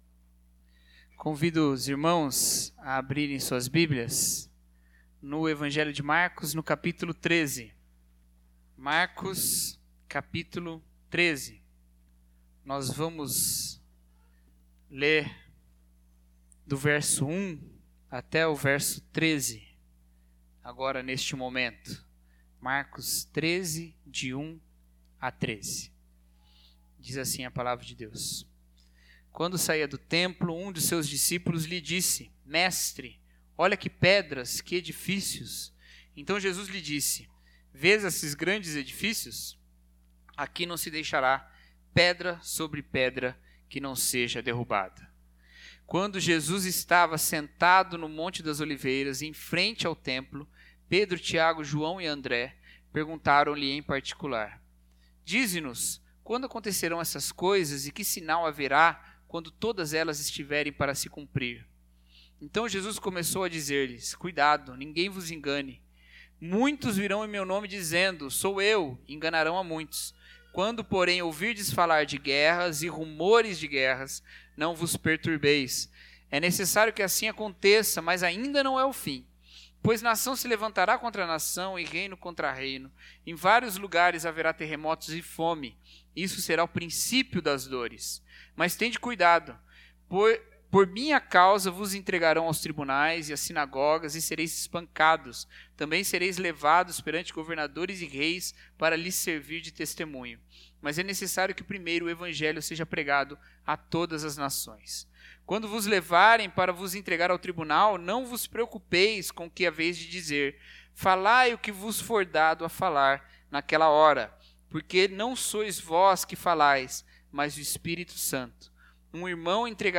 O Reino em Movimento Mensagem